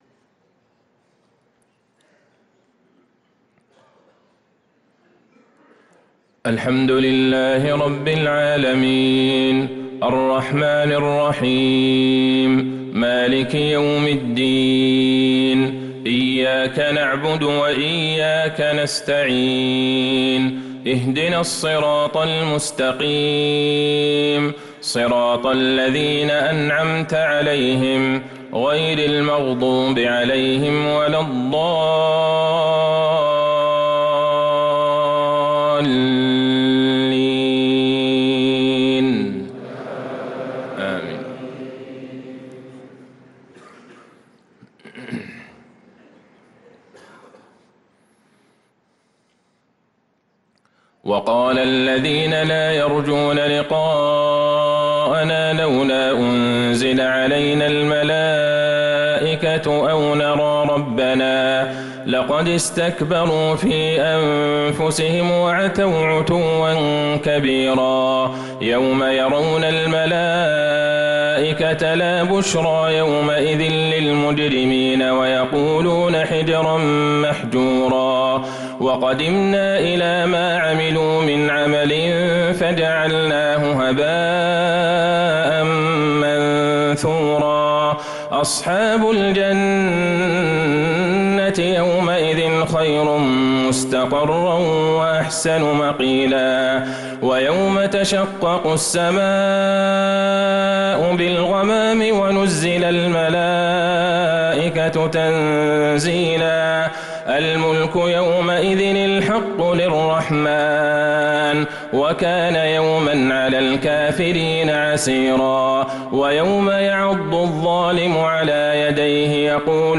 صلاة العشاء